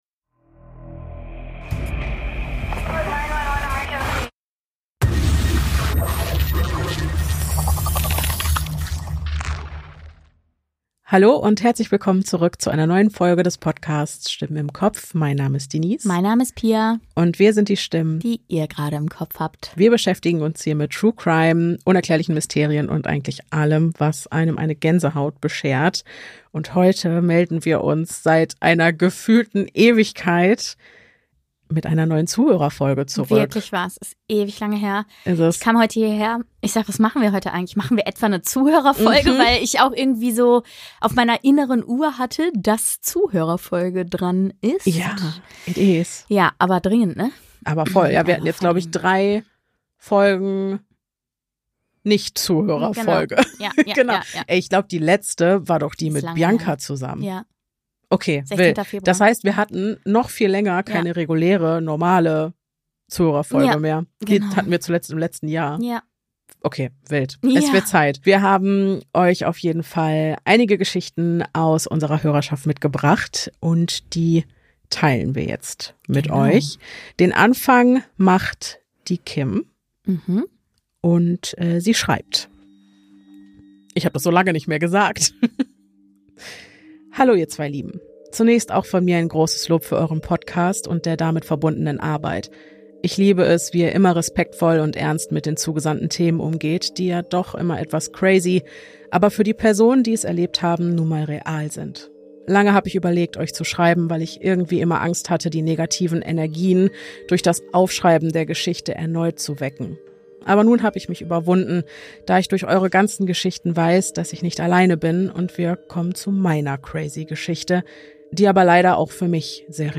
Pro Folge wird jeder von uns eine Geschichte vortragen, mit dem Ziel den anderen in Angst und Schrecken zu versetzen.